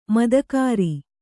♪ madakāri